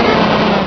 Cri de Snubbull dans Pokémon Rubis et Saphir.